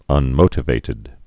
(ŭn-mōtə-vātĭd)